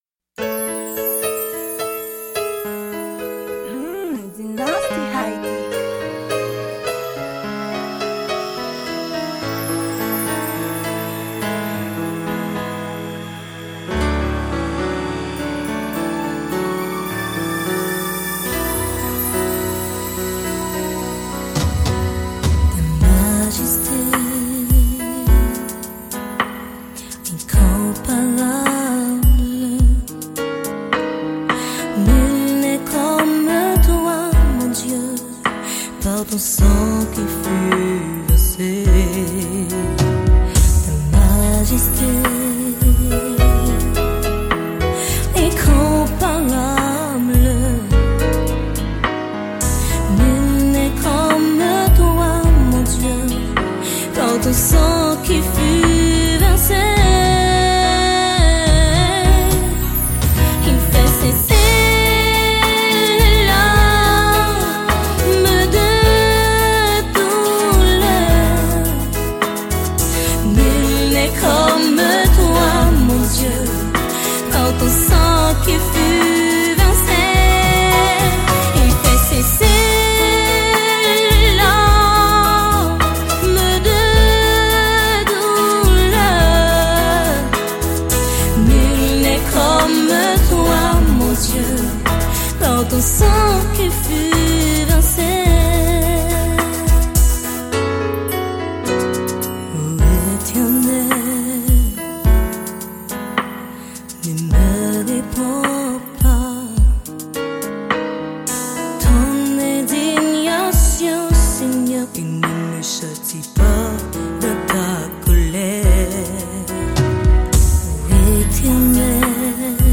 Genre: Religion Song.